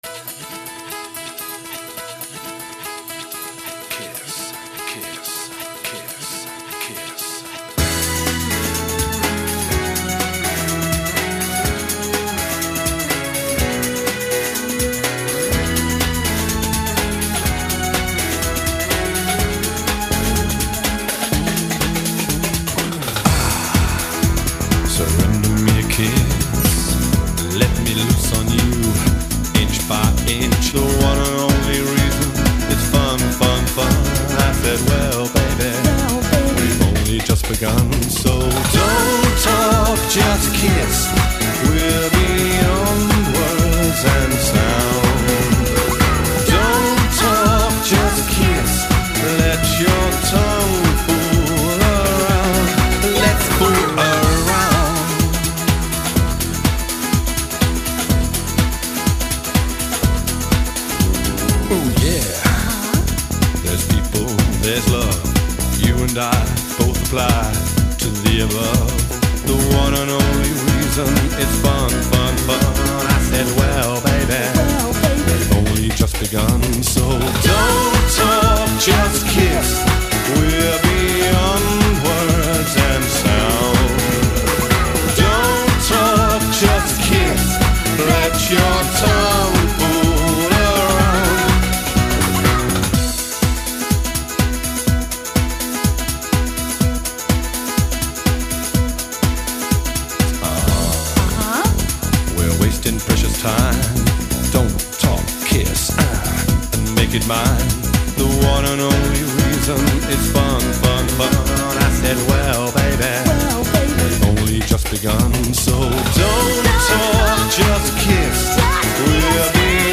is an American R&B and dance music singer.